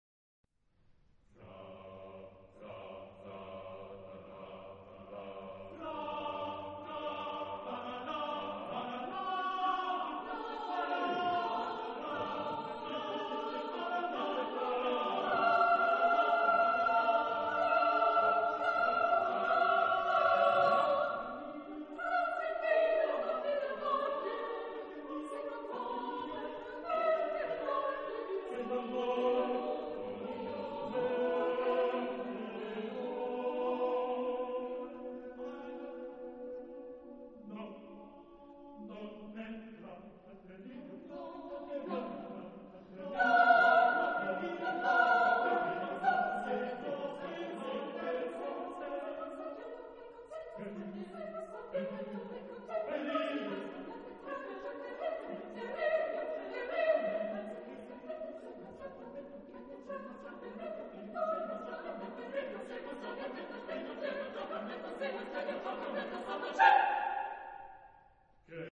Genre-Style-Form: Partsong ; Contemporary ; Secular
Type of Choir: SATB  (4 mixed voices )
Tonality: polytonal